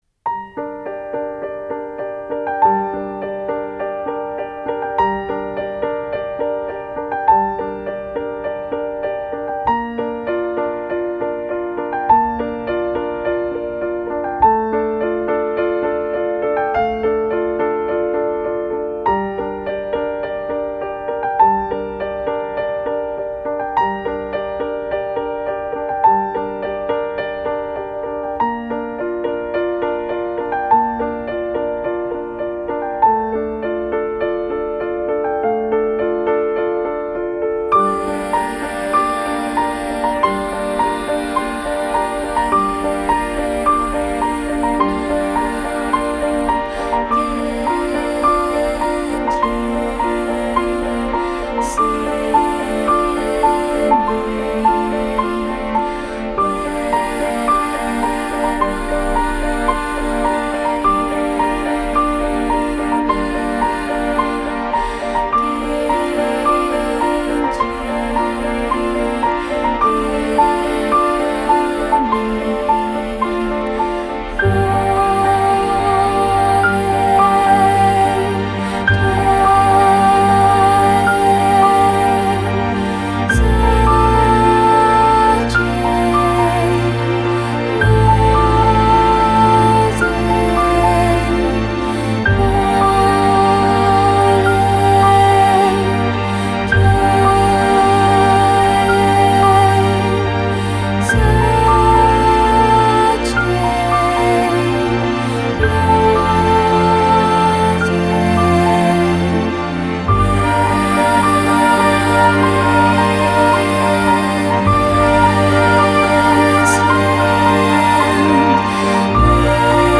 Soundtrack, Neo-Classical, Minimal